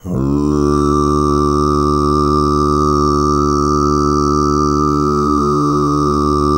TUV3 DRONE04.wav